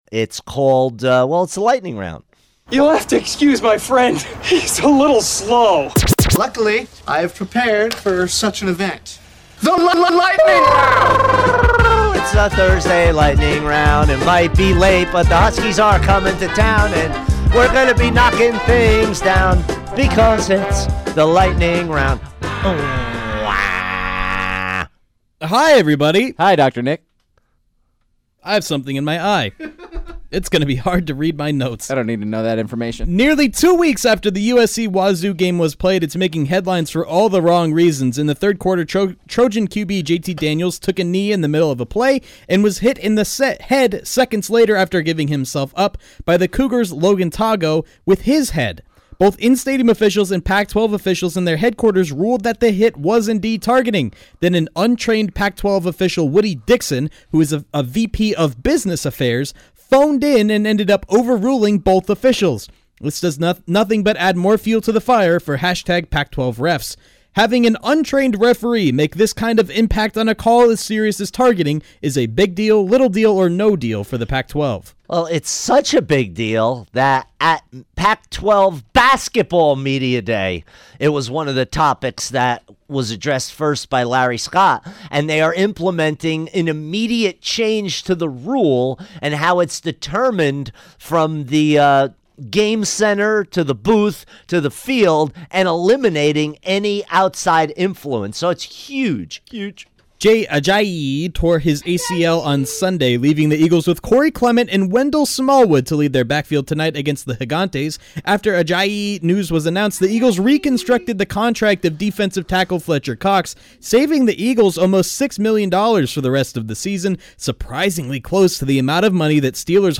fires through the big sports stories of the day rapid-fire style